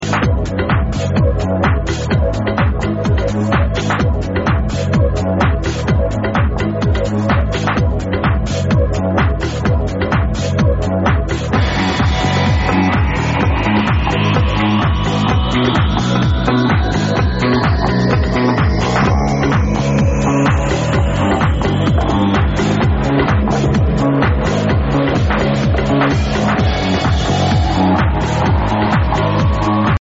on an italian radio in 2000